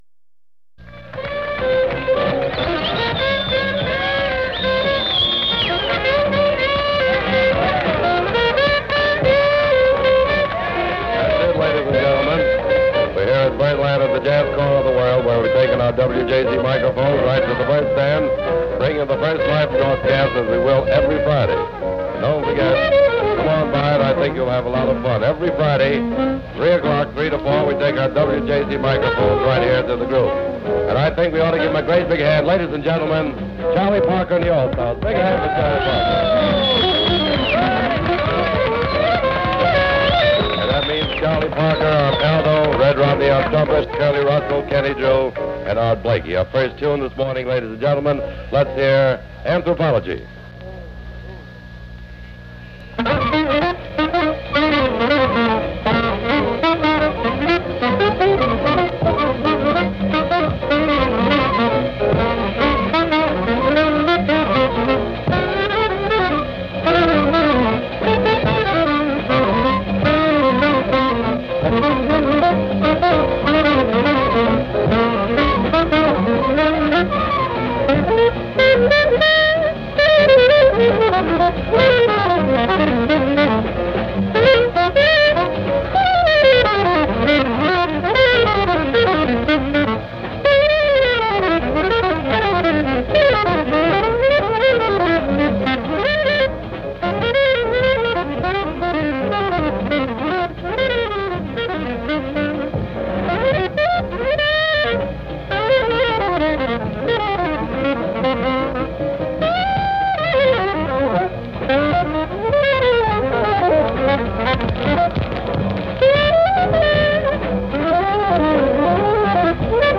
trumpet
piano
bass
drums